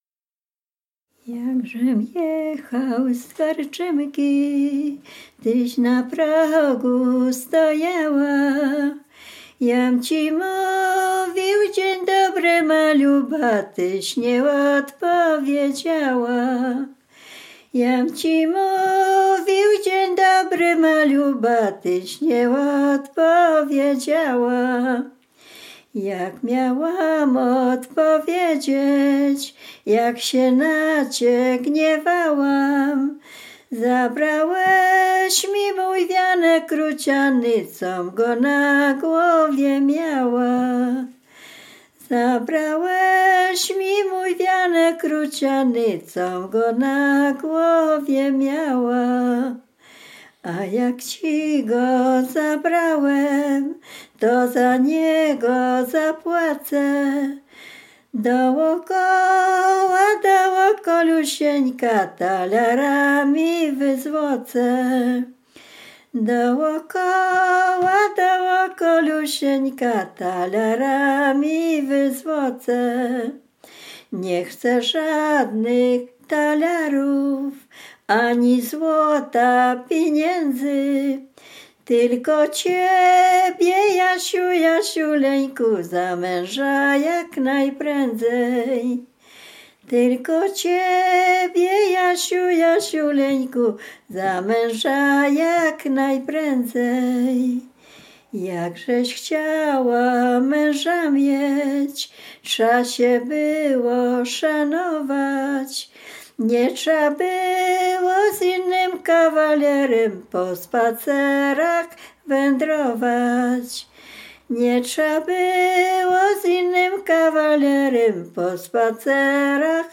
Dolny Śląsk, powiat bolesławiecki, gmina Osiecznica, wieś Przejęsław
liryczna miłosna